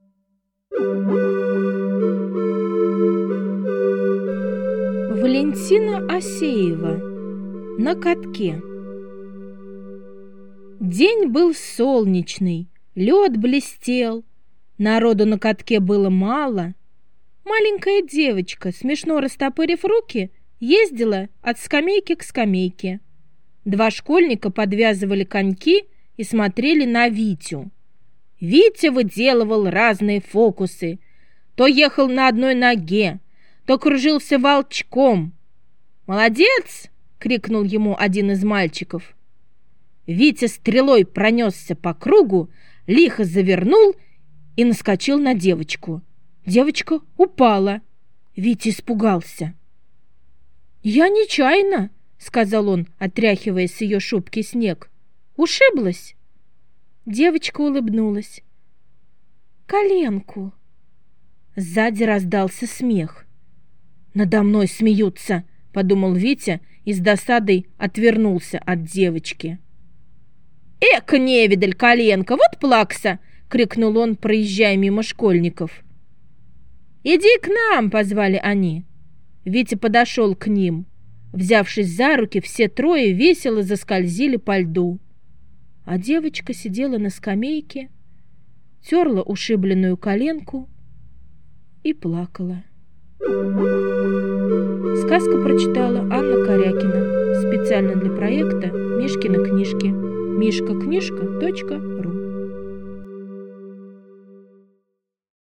На катке - аудио рассказ Осеевой В. Рассказ про мальчика Витю, который с друзьями катался на катке и нечаянно сбил маленькую девочку...